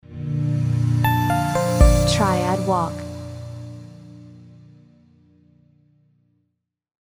Jingle] Intro for videos, news, etc.